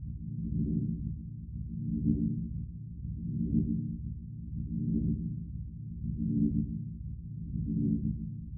Drill/factory/generator noises
bioLoop.ogg